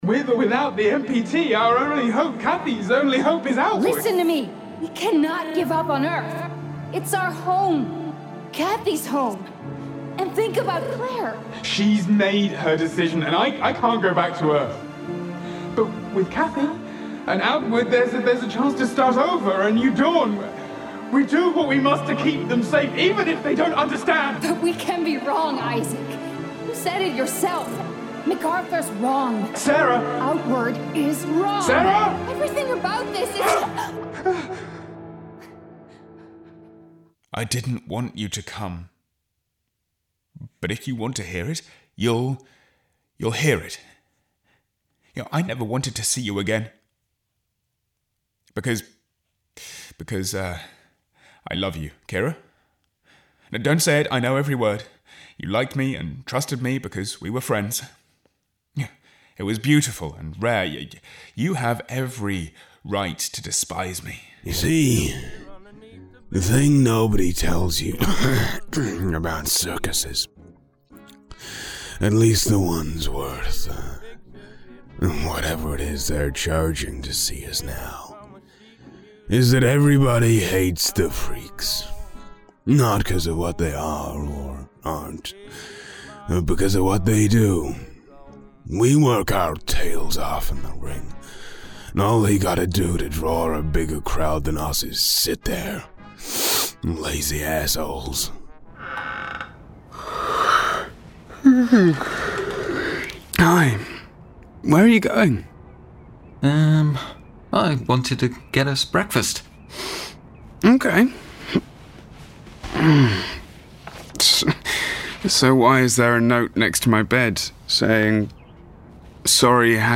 Englisch (Britisch)
Natürlich, Zuverlässig, Warm, Freundlich, Corporate
Persönlichkeiten